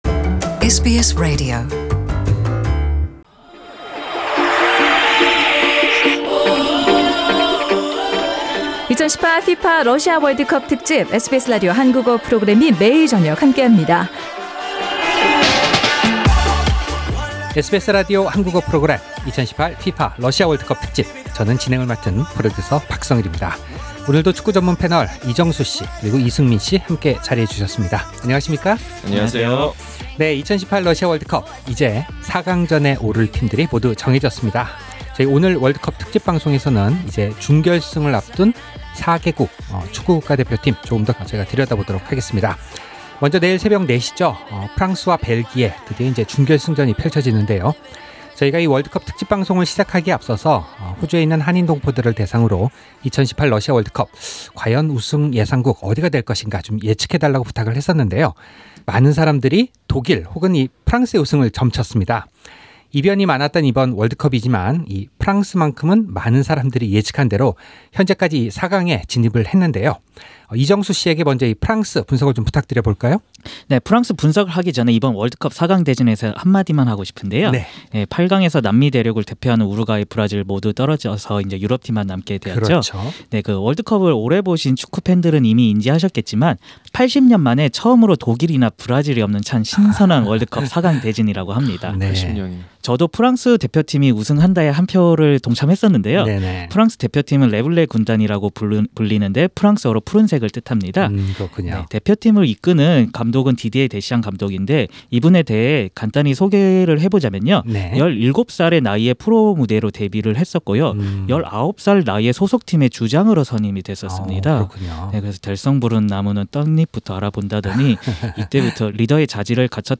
The World Cup panel of two in Melbourne